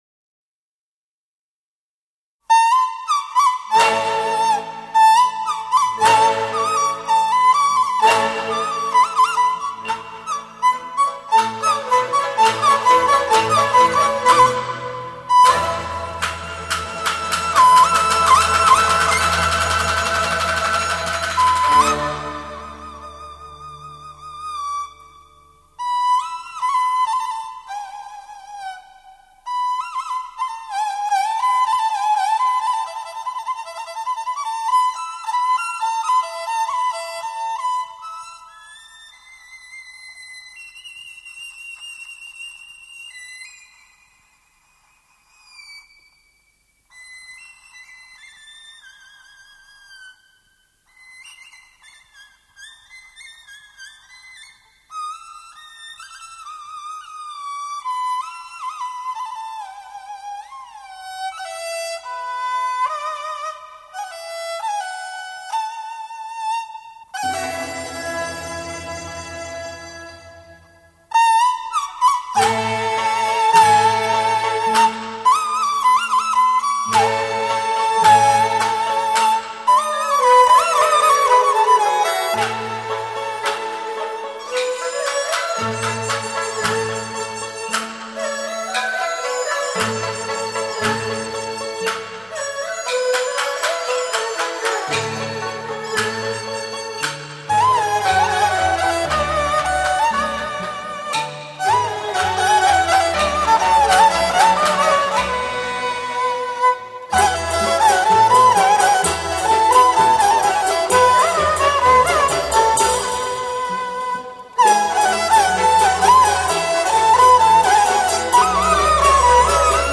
其演奏风格洒脱而细腻。